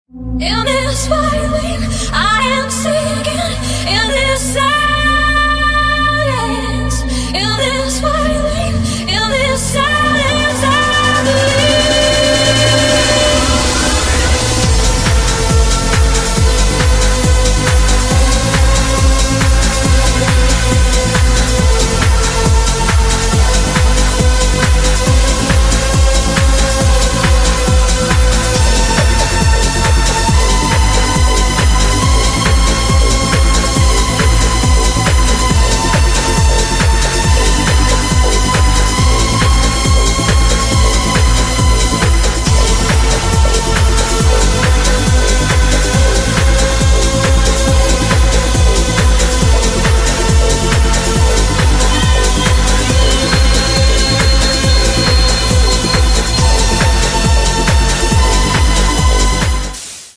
trance anthem
😵‍💫 That iconic vocal. That build. That drop.